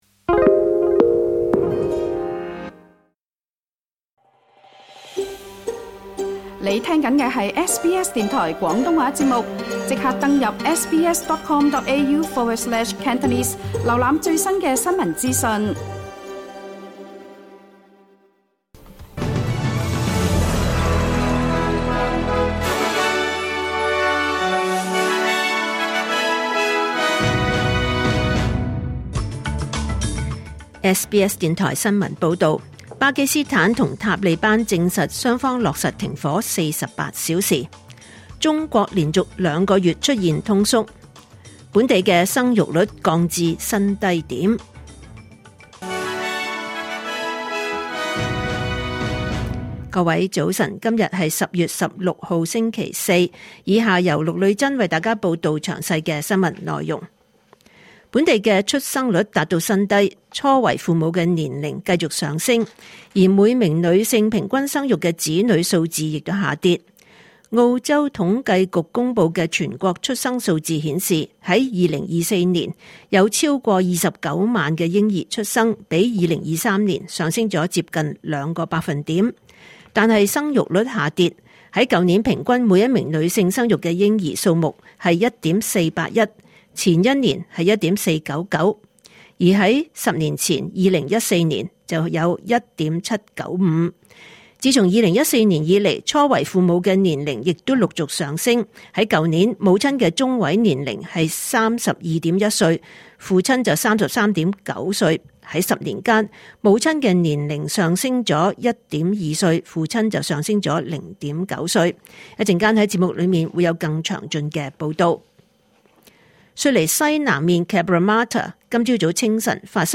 2025年10月16日SBS廣東話節目九點半新聞報道。